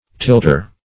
Tilter \Tilt"er\, n.